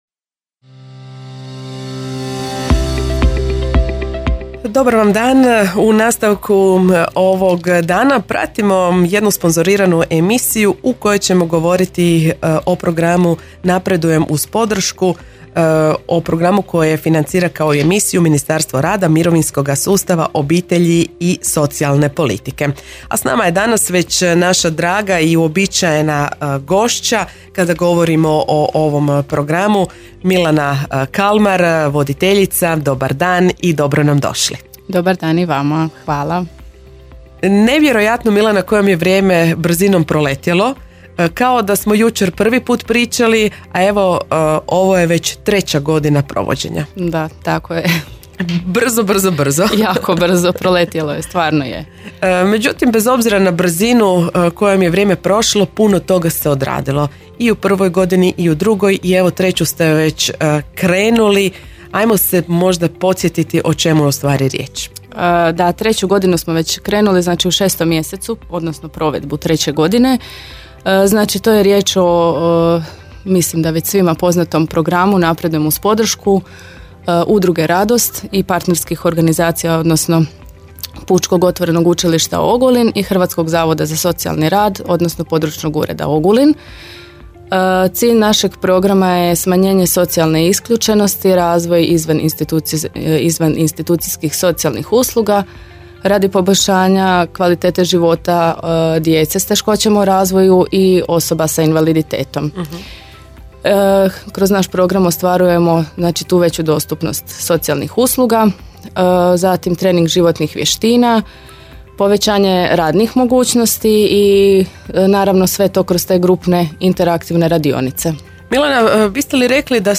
Radio Ogulin – radijska emisija Radio Ogulin